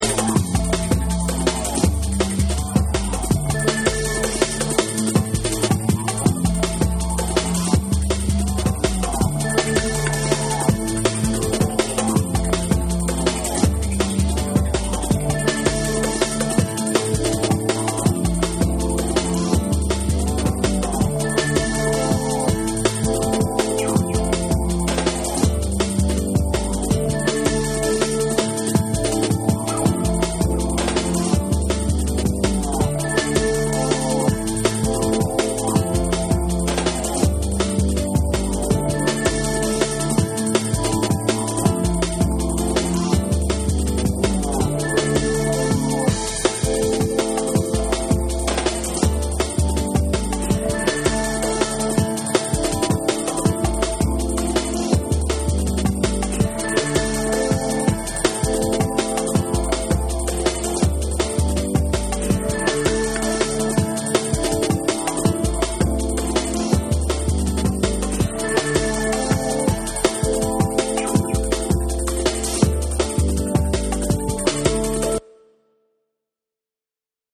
REGGAE & DUB / ORGANIC GROOVE